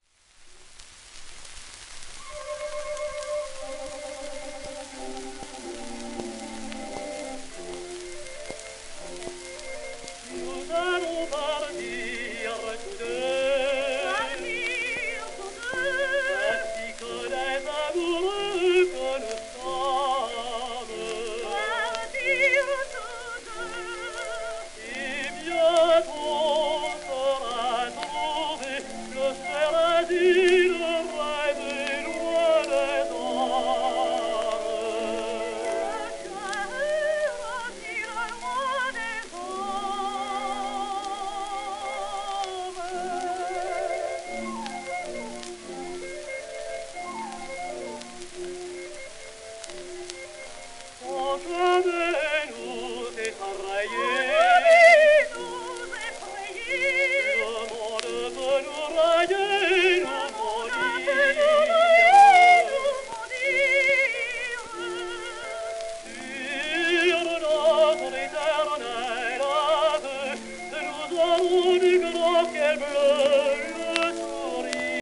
オーケストラ
10インチ 片面盤
旧 旧吹込みの略、電気録音以前の機械式録音盤（ラッパ吹込み）